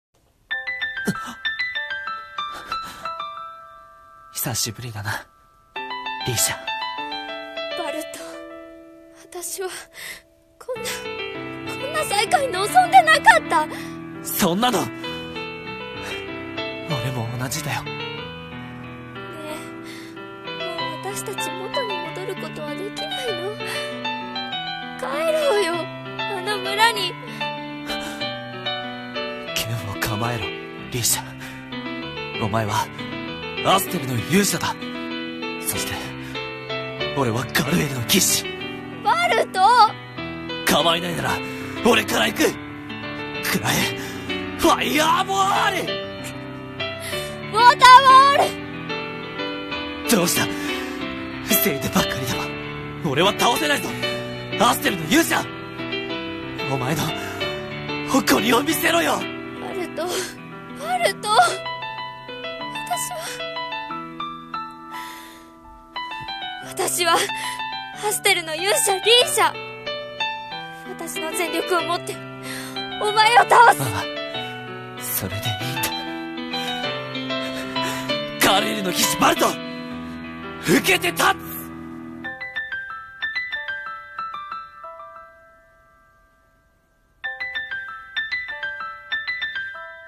声劇